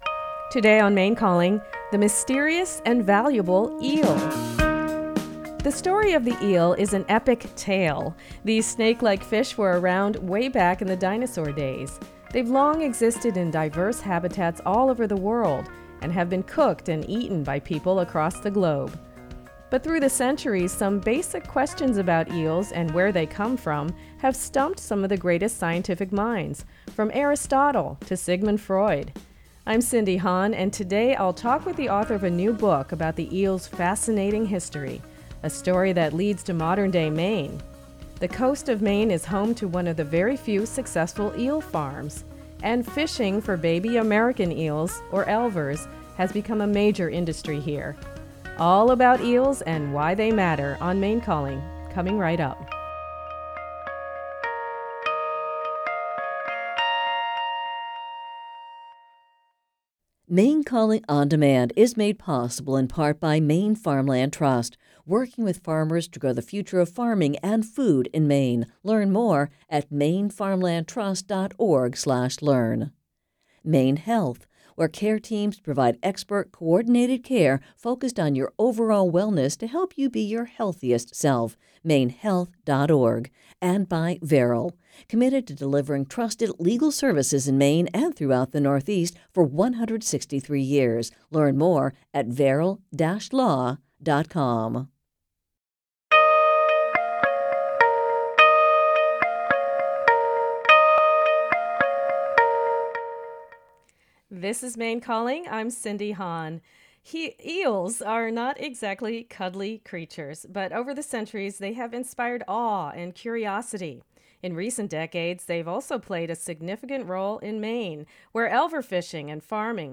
Maine Calling is a live, call-in radio program offering enlightening and engaging conversations on a wide range of topics.